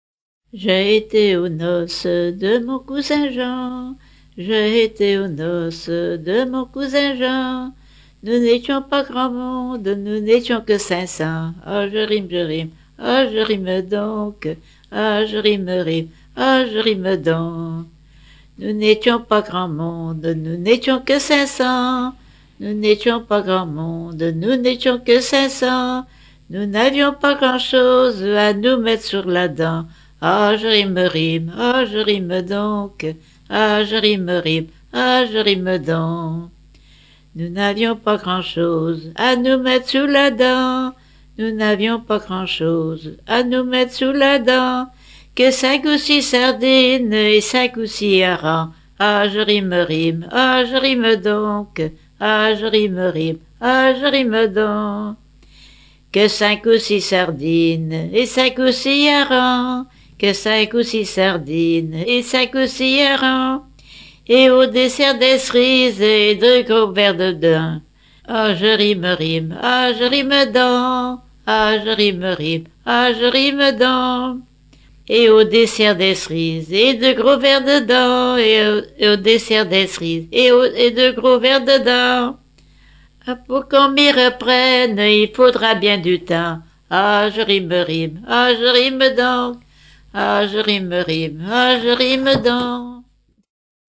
Dompierre-sur-Yon
Genre laisse
Pièce musicale éditée